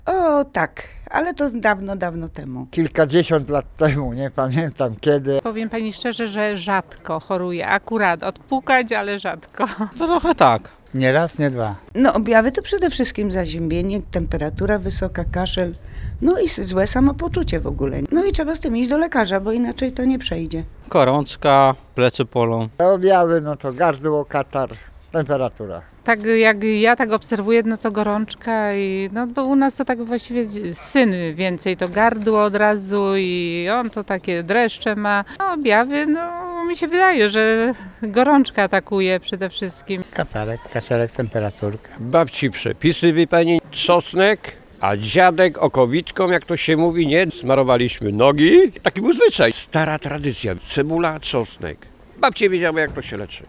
A czy mieszkańcy Pałuk również chorują i wiedzą jakie są objawy grypy?